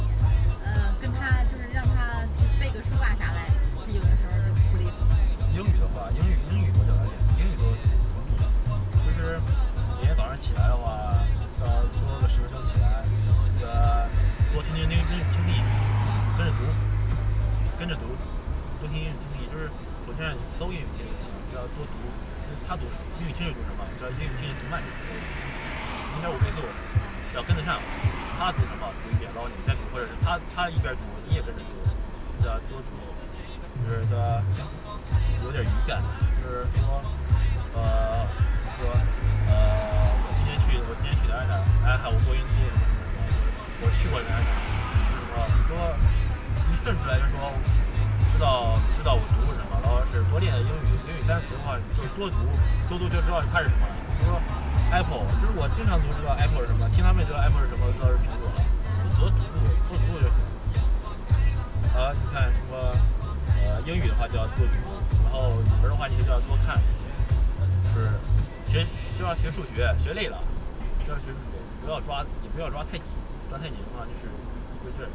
• 超百人的车内驾驶员和乘客间语音对话数据，覆盖不同驾驶场景；
本次赛事发布一个真实的车内多通道语音语料库，包含约1000 **+**小时、车内真实录制的多通道普通话语音数据，收音设备包含车内分布式麦克风收集的远场数据，以及参与者的头戴麦克风收集的近场数据。